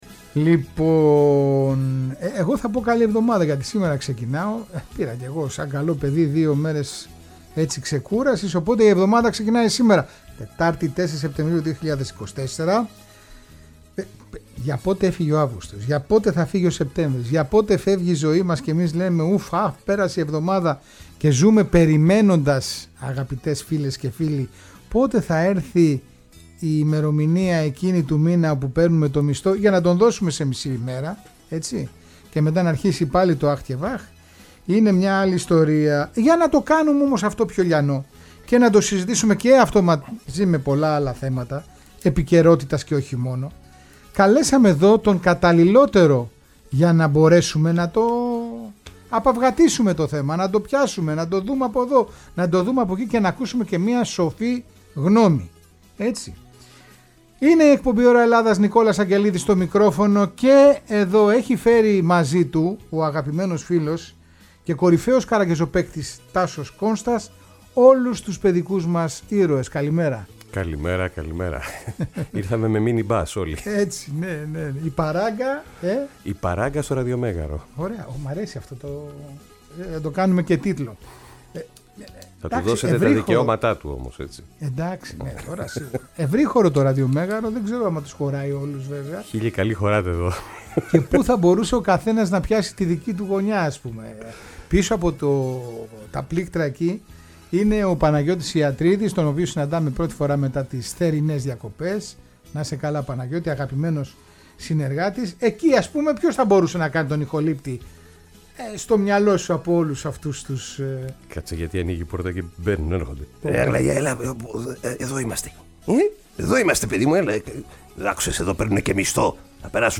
Η παράγκα στήνεται στο Ραδιομέγαρο!
Θεατρο Σκιων